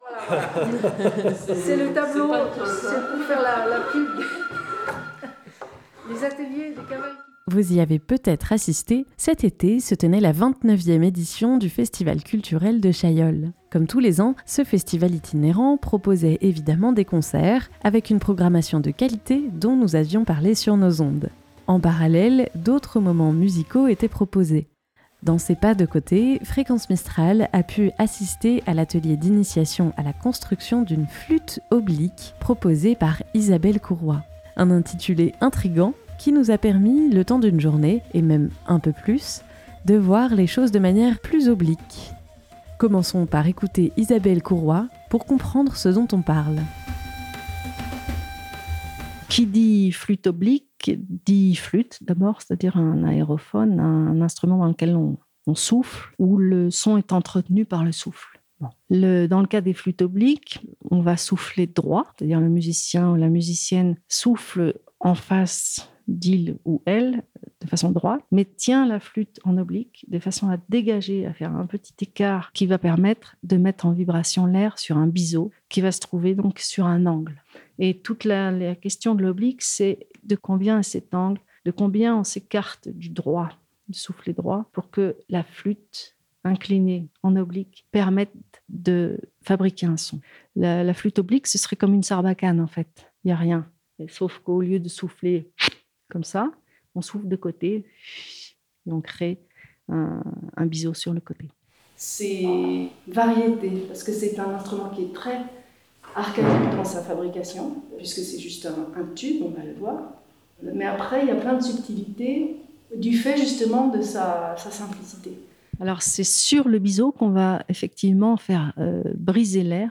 En attendant que la saison reprenne, voici un peu du souffle qui nous a été proposé en juillet : 250730 - ECC Atelier flute oblique.mp3 (54.93 Mo)